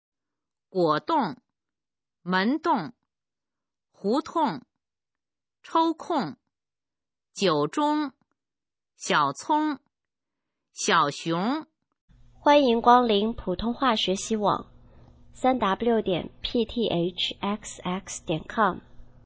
普通话水平测试用儿化词语表示范读音第18部分